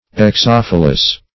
exophyllous - definition of exophyllous - synonyms, pronunciation, spelling from Free Dictionary
Search Result for " exophyllous" : The Collaborative International Dictionary of English v.0.48: Exophyllous \Ex*oph"yl*lous\, a. [Exo- + Gr.
exophyllous.mp3